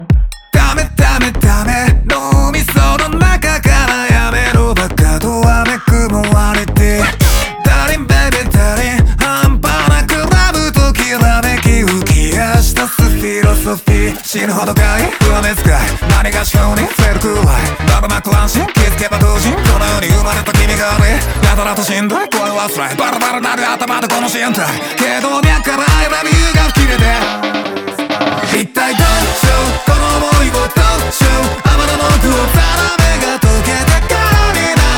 Жанр: Поп музыка
J-Pop